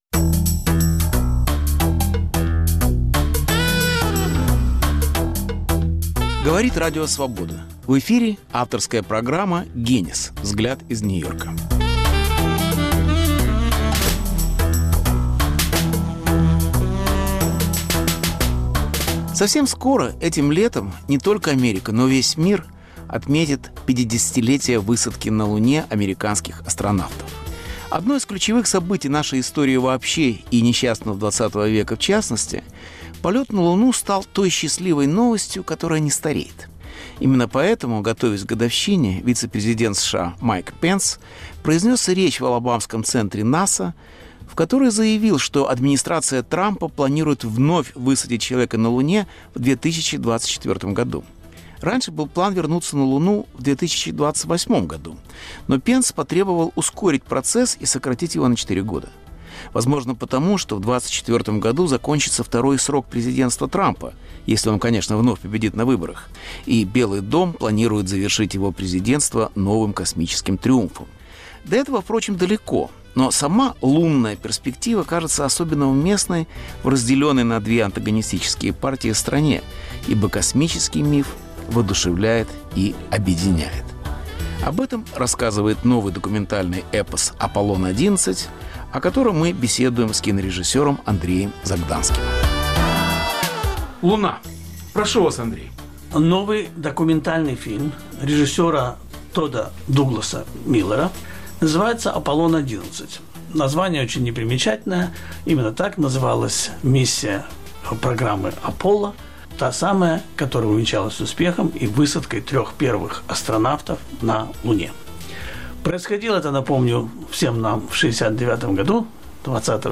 Путь к Луне: психоделическая эпопея “Аполлона-11”. Беседа